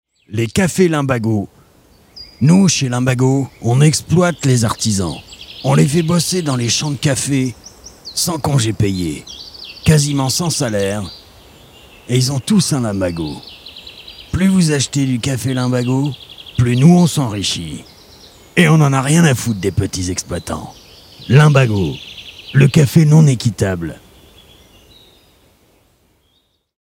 Fausses Pubs RADAR